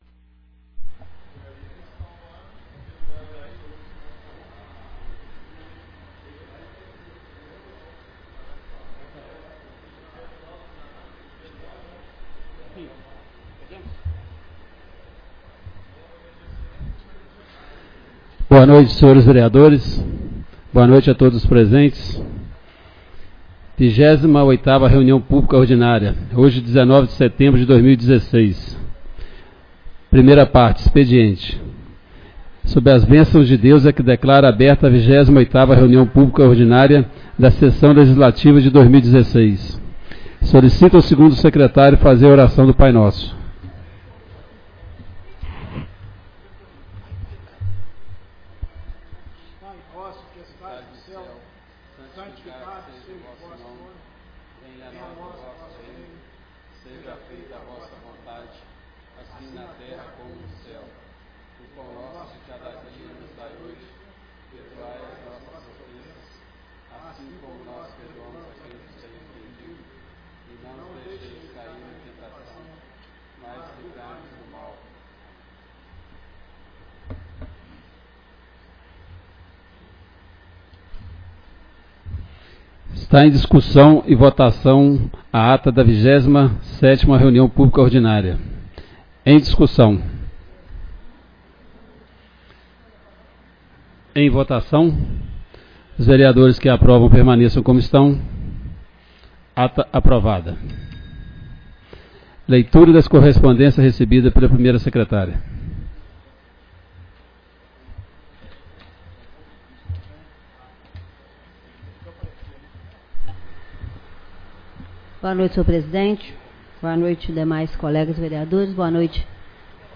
28ª Reunião Pública Ordinária